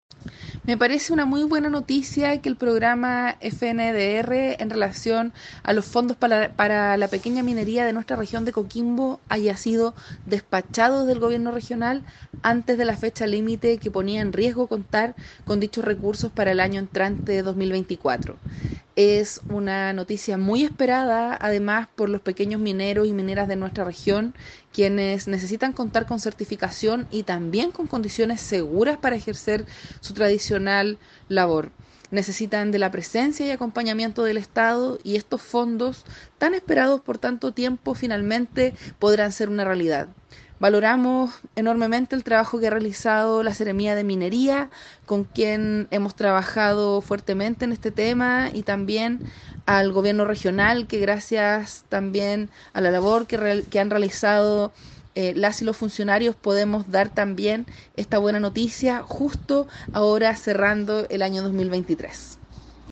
La diputada Carolina Tello afirmó que